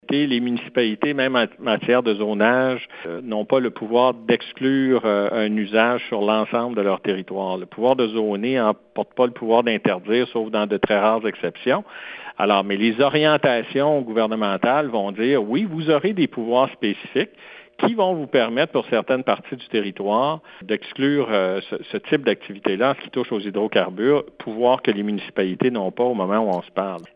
C’est ce que Pierre Moreau a affirmé en entrevue avec Radio Gaspésie, aujourd’hui.